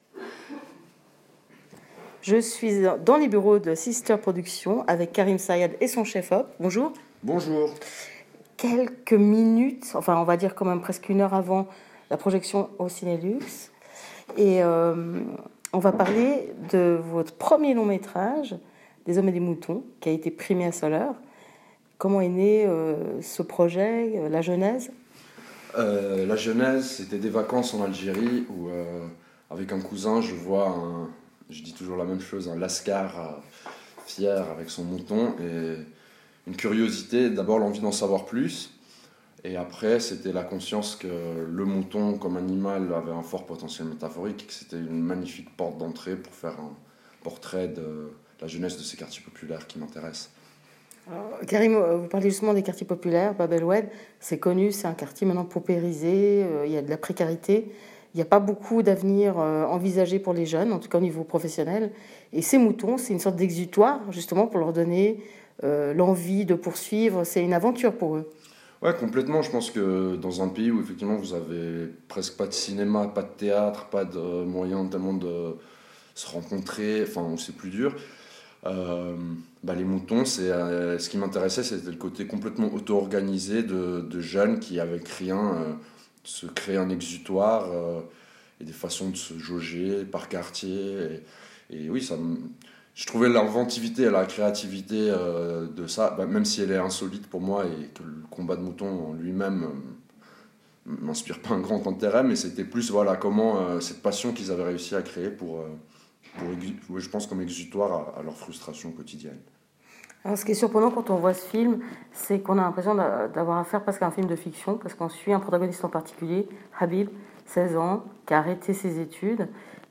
Rencontre [audio]